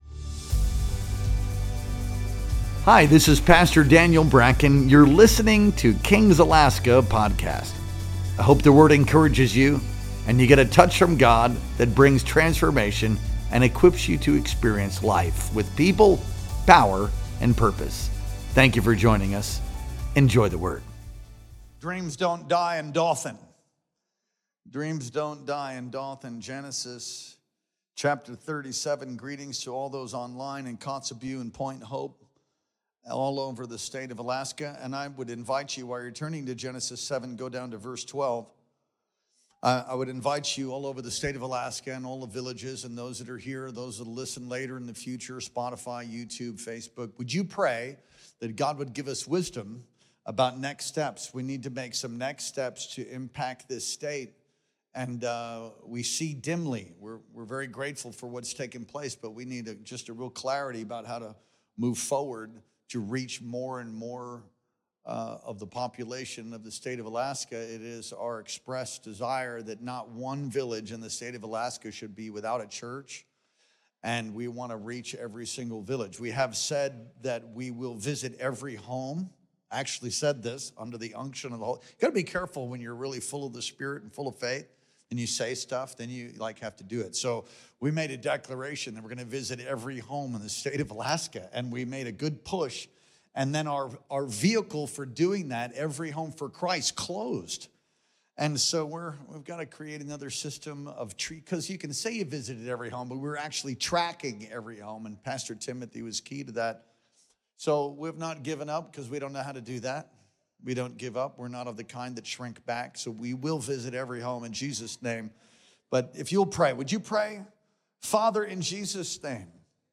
Our Wednesday Night Worship Experience streamed live on April 30th, 2025.